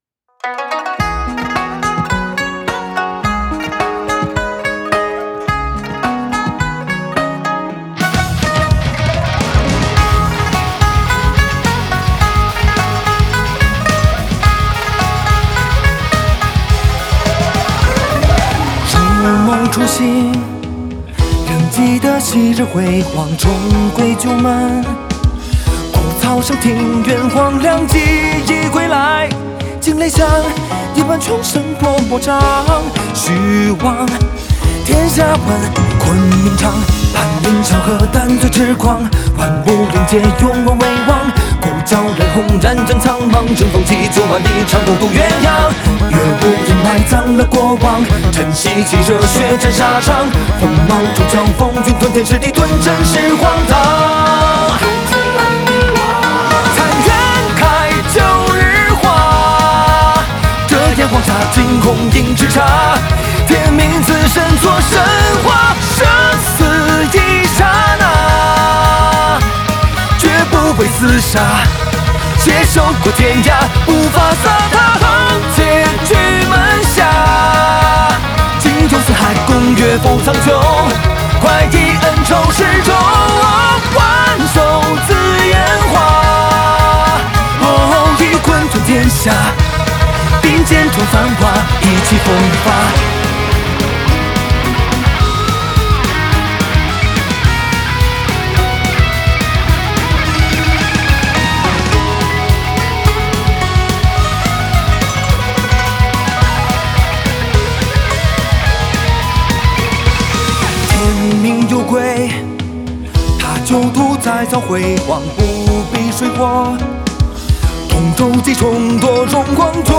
Ps：在线试听为压缩音质节选，体验无损音质请下载完整版
动画片头曲